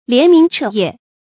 連明徹夜 注音： ㄌㄧㄢˊ ㄇㄧㄥˊ ㄔㄜˋ ㄧㄜˋ 讀音讀法： 意思解釋： 見「連明連夜」。